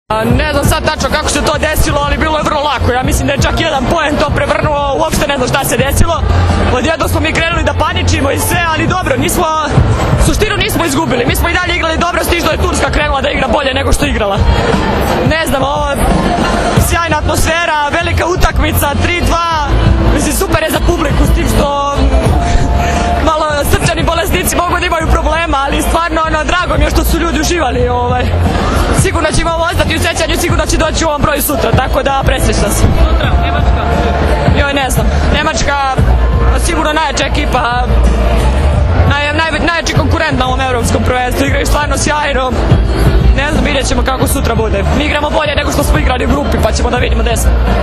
IZJAVA SANJE MALAGURSKI